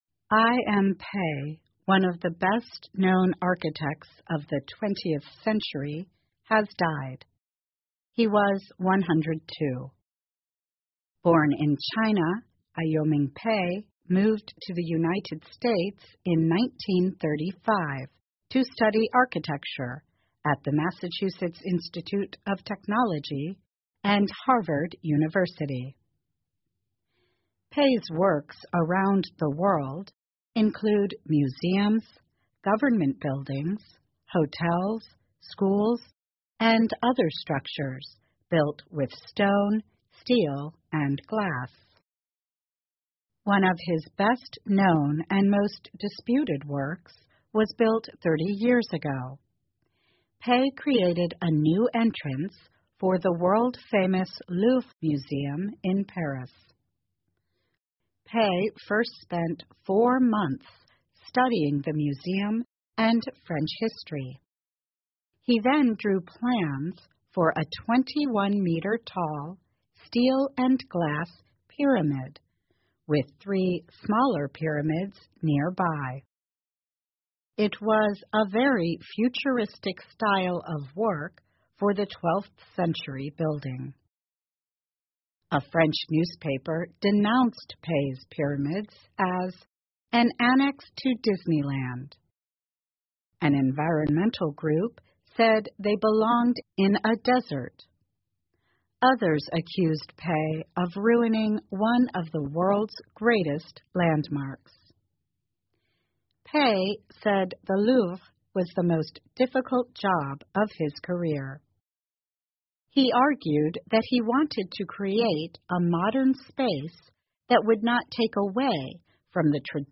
VOA慢速英语--华裔建筑大师贝聿铭去世 享年102岁 听力文件下载—在线英语听力室